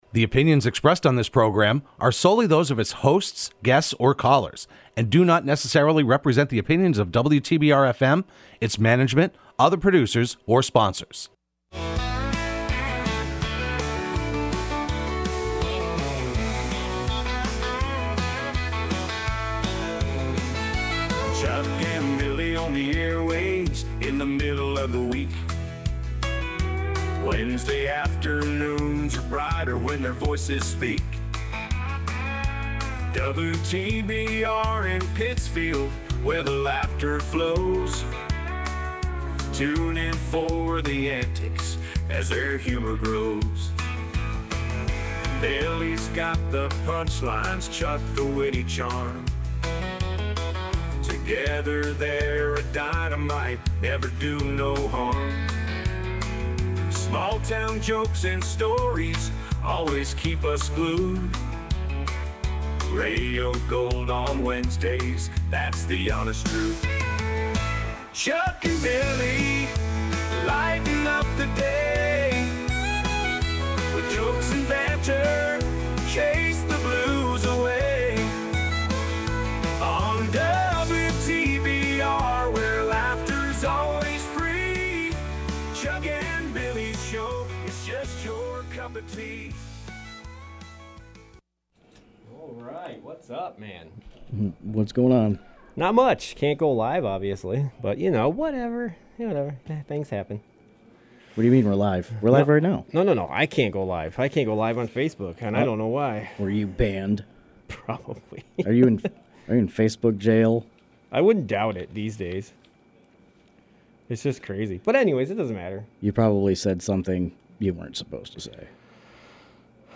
Broadcast live every Wednesday afternoon at 4pm on WTBR.